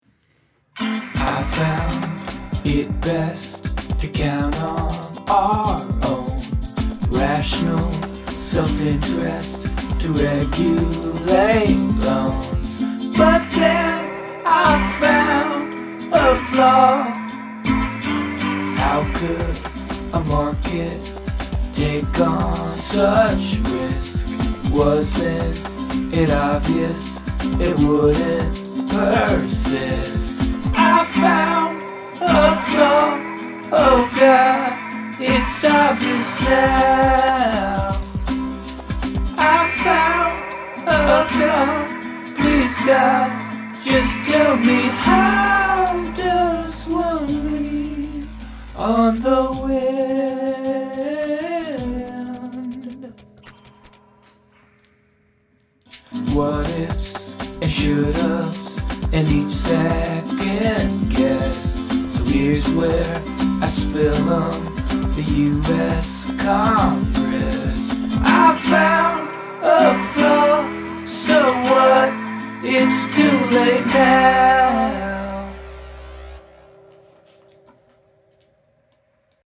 verse: G, C, G, C, G, C, F (x2)
chorus: F, C, C, E7, E7, Am, Am, Dm, G7, C
the "lean on the wind" line is also from a greenspan quote. anyway, i thought it was very interesting that a guy could sit in front of congress and say that after 40 years he may have been completely wrong about his world view. pretty wild. i wrote the chord and melody this morning. the verse came first, then the chorus, and then the bridge. i recorded two strumming guitar parts, one with a capo. i also put down another track of acoustic guitar on the chorus and put it through some crazy processor so it sounds all bizarre. i messed around with some beats, a buzzy synth bass and threw some bells on the chorus. i think the 2nd and 3rd verses could be better, and the song sounds pretty frantic, at this tempo its only 1:20, and it goes through 3 verses, 4 choruses, and a bridge. not sure that's the best way to play it. i'll see how it sounds in the morning. i've been hoping to write more songs about the economy to go with the accountants one.